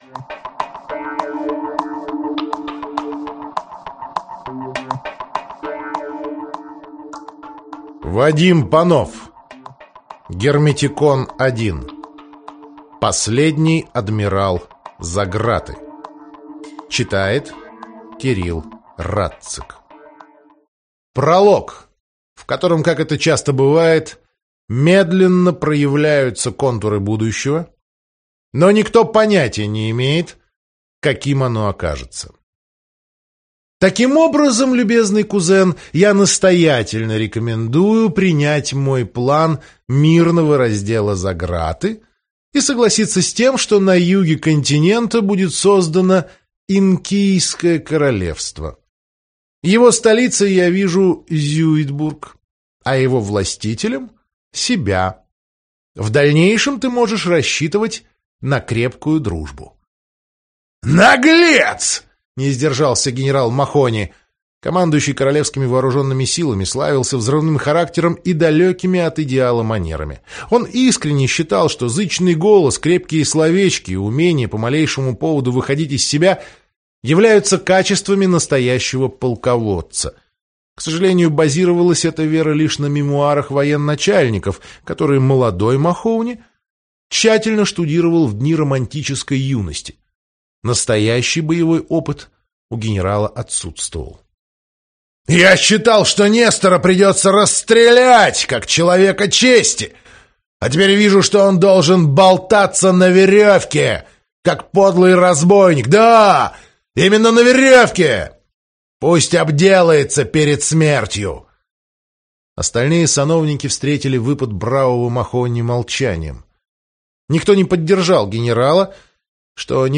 Аудиокнига Последний адмирал Заграты - купить, скачать и слушать онлайн | КнигоПоиск